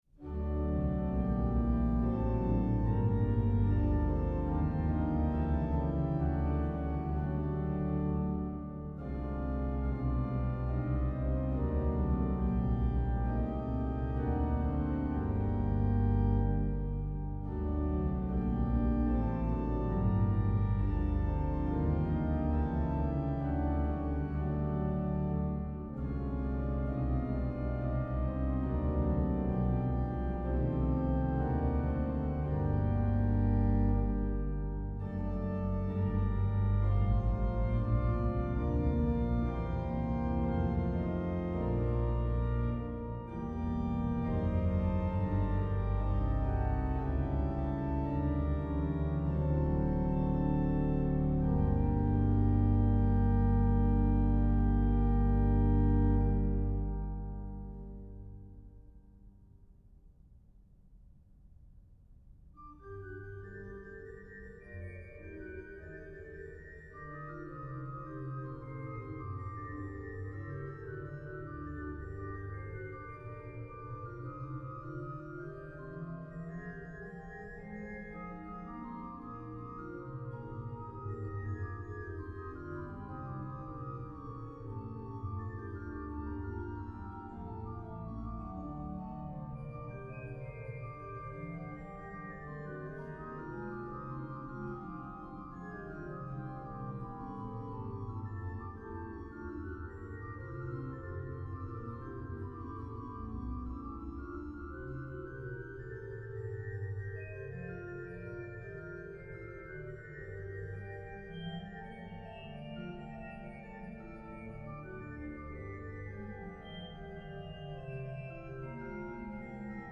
→ Orgeln in St. Kastor → Beschreibung der neuen Orgel Orgel von St. Kastor Koblenz Video vom Bau der Orgel Hörprobe der Orgel: Wer nur den lieben Gott lässt walten.
02-Choralpatita-Wer-nur-den-lieben-Gott-lässt-walten.mp3